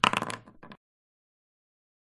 • 投掷的时候, 骰子应该播放一点点声音.
rollDice.ogg